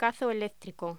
Locución: Cazo eléctrico
voz
Sonidos: Hostelería